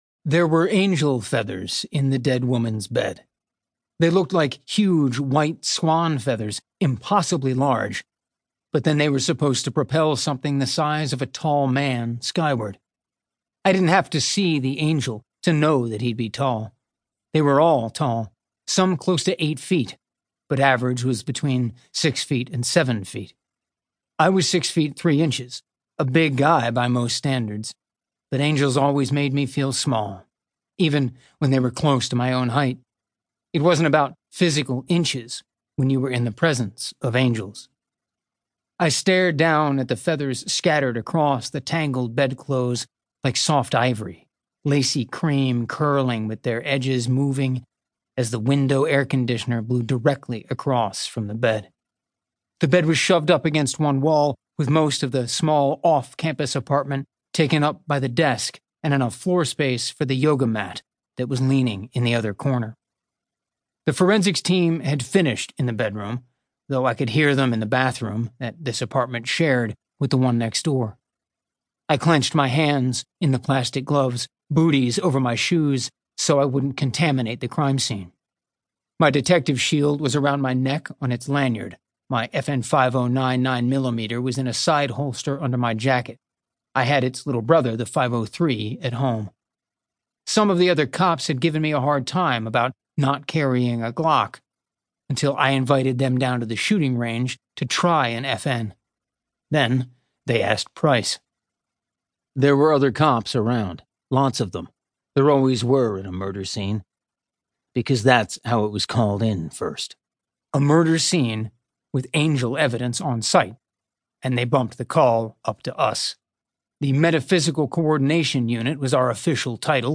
• Audiobook
(P) 2021 Penguin Audio